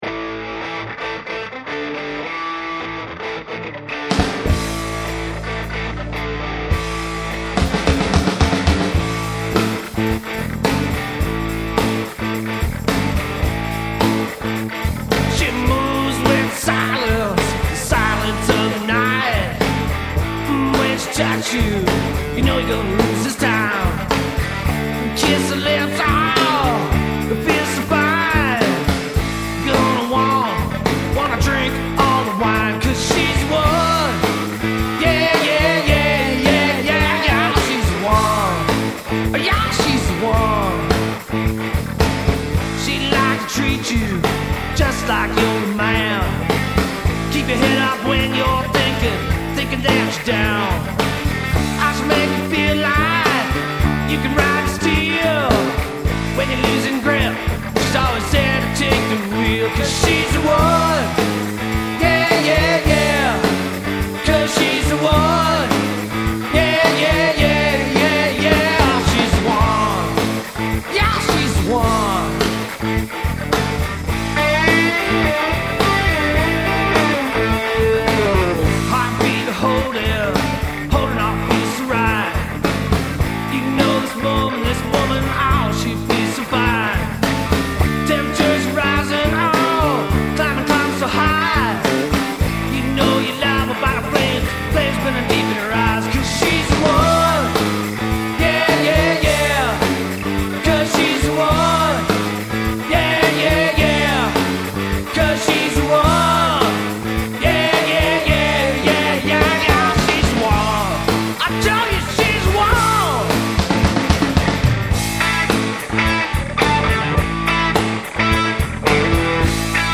Cornerstone is a local group of musicians playing some of today's best rock, alternative radio played and original music.